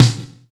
28 SNARE.wav